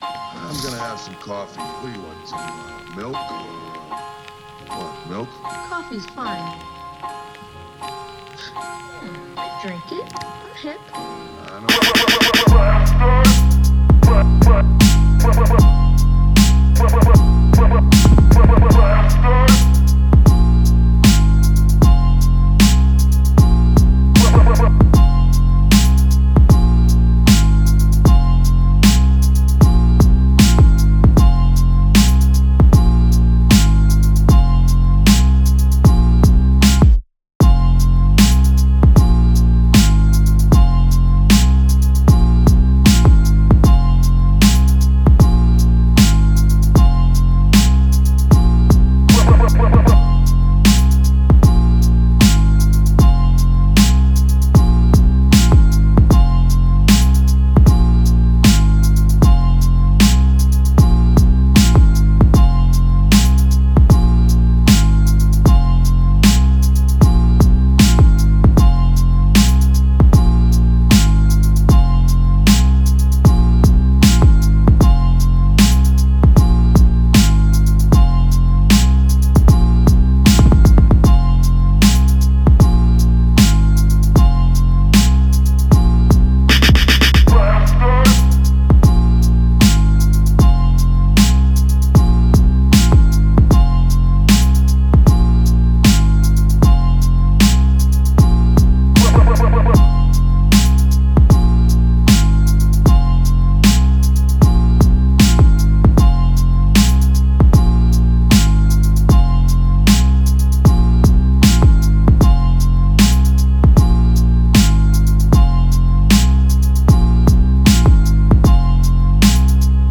BPM138
NOTADm
MOODHard
GÉNEROTrap